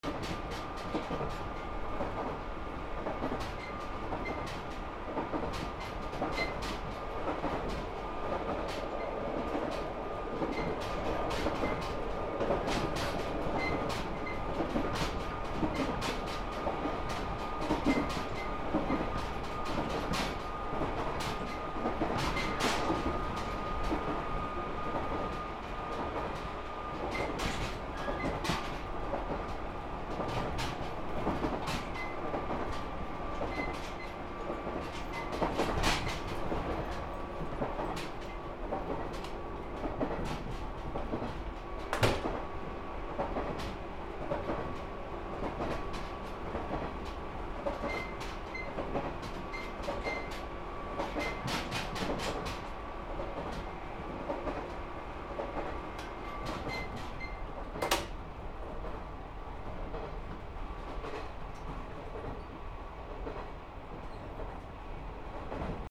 電車走行 大きく揺れる
/ E｜乗り物 / E-60 ｜電車・駅